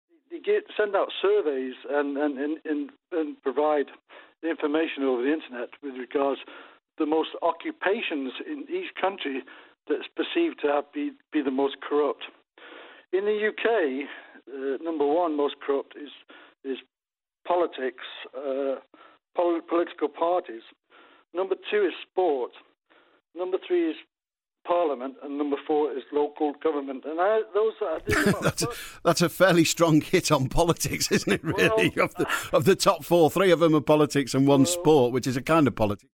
first call tonight talking about corruption